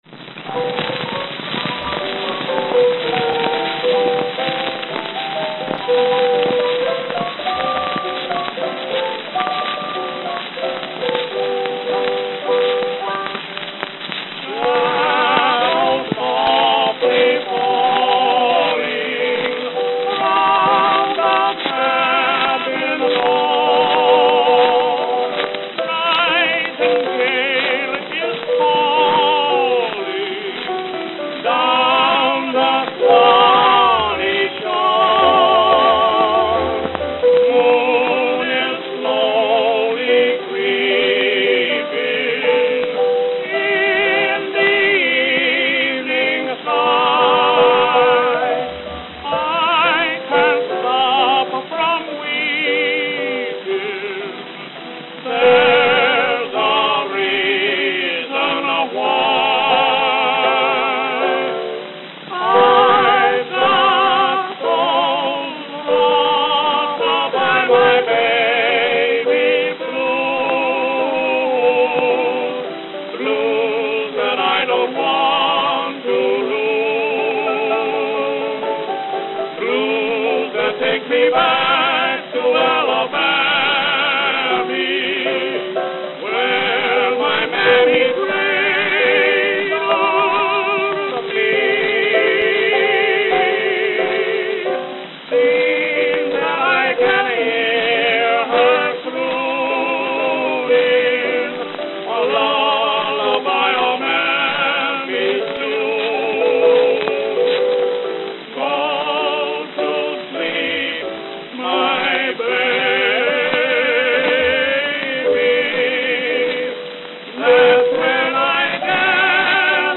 Note: Very worn.
Abrupt start and end on Side-B.